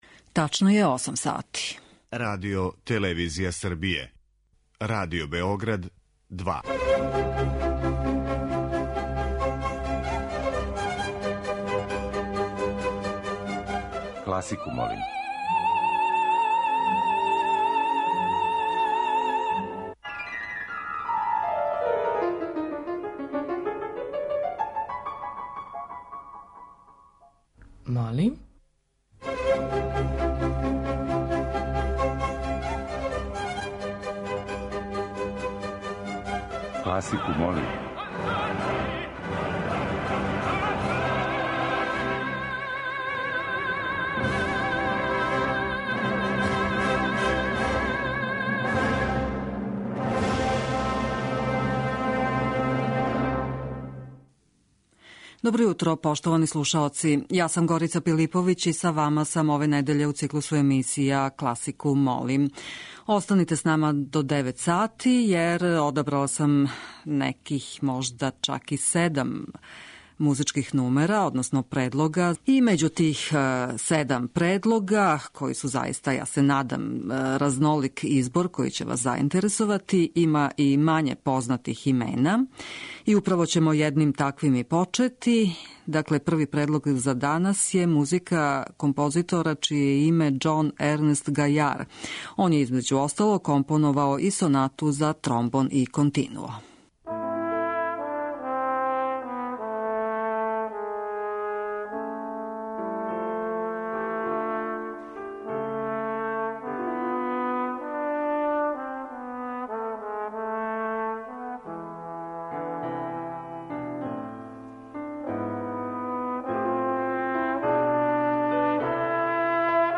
Оперске улоге лирских сопрана
Избор за недељну топ-листу класичне музике Радио Београда 2